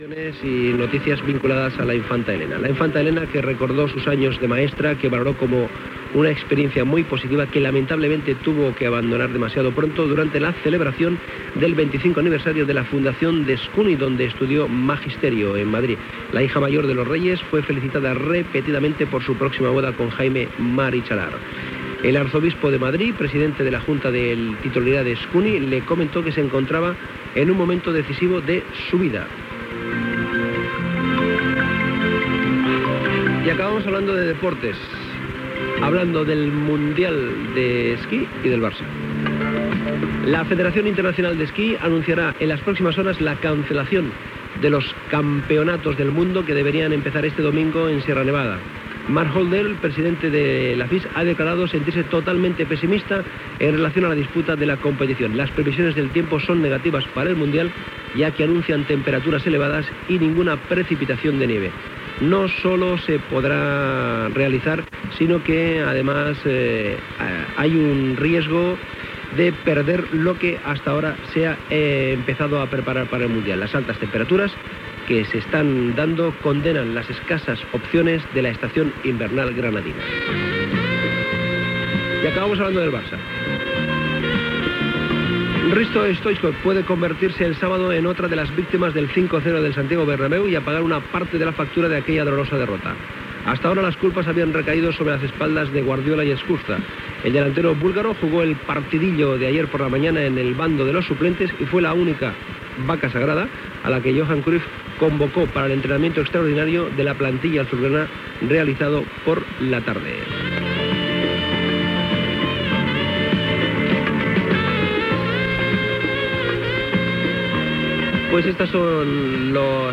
Indicatiu del programa. Publicitat. Indicatiu de l'emissora.
Info-entreteniment